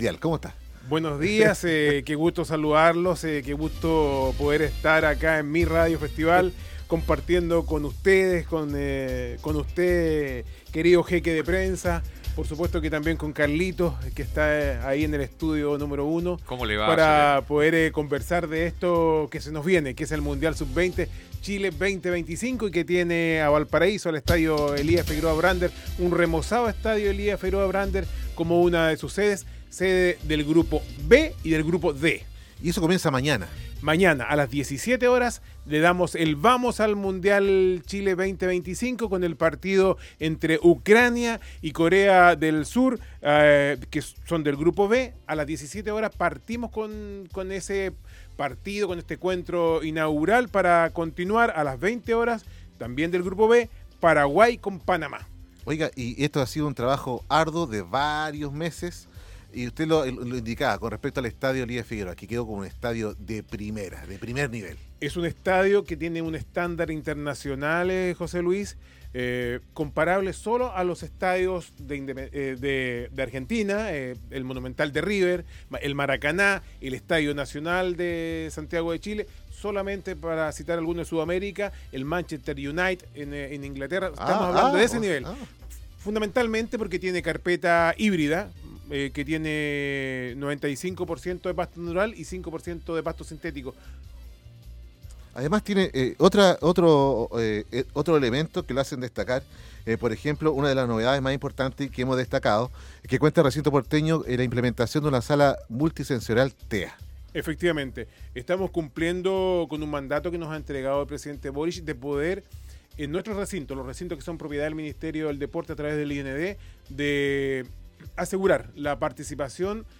El seremi del Deporte Leandro Torres conversó con Radio Festival para invitar a todos a participar de este encuentro deportivo que se vivirá en nuestra región